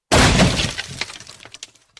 coffin_shatter.ogg